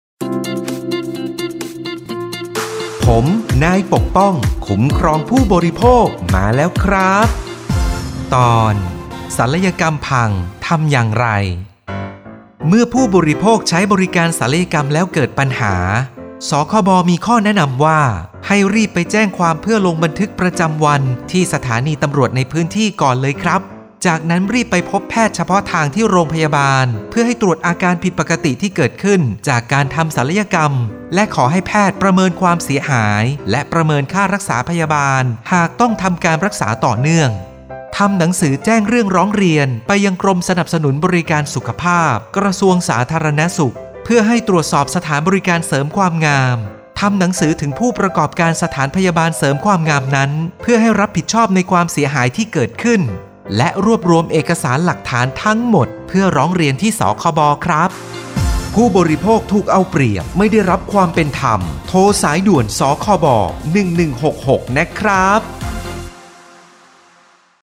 สื่อประชาสัมพันธ์ MP3สปอตวิทยุ ภาคกลาง
024.สปอตวิทยุ สคบ._ภาคกลาง_เรื่องที่ 24_.mp3